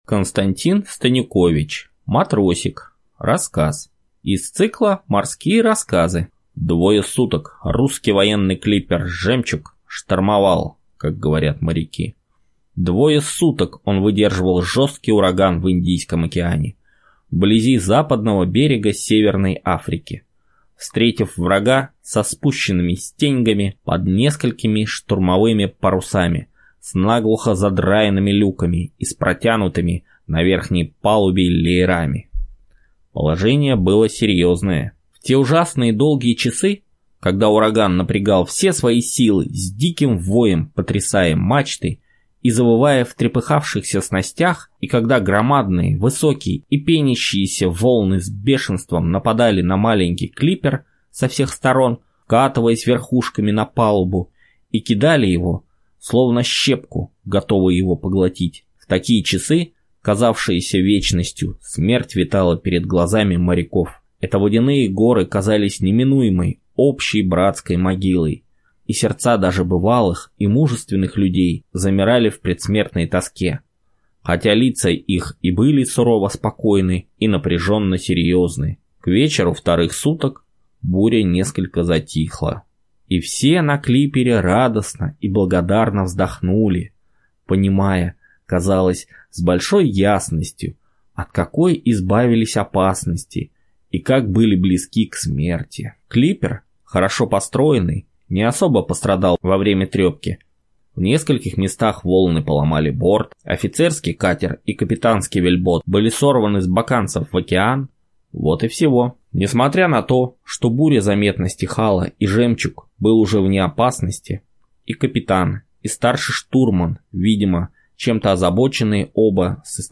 Матросик — слушать аудиосказку Константин Станюкович бесплатно онлайн
На данной странице вы можете слушать онлайн бесплатно и скачать аудиокнигу "Матросик" писателя Константин Станюкович.